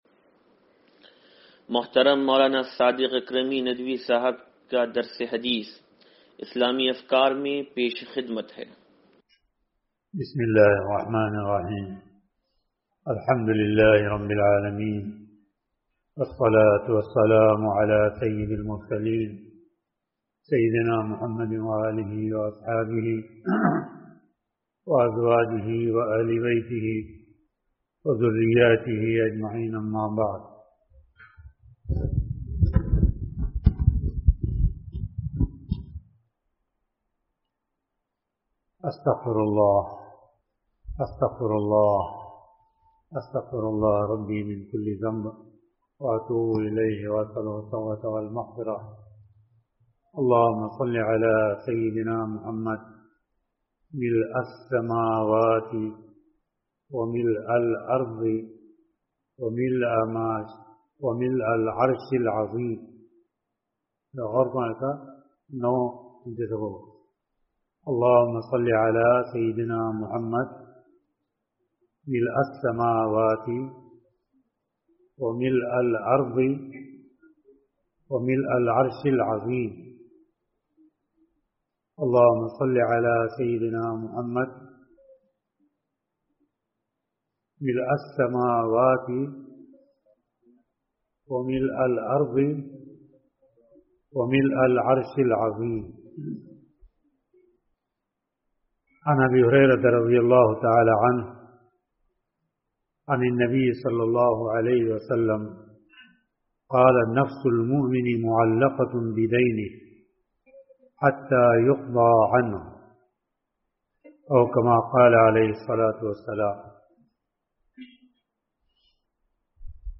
درس حدیث نمبر 0740
(سلطانی مسجد)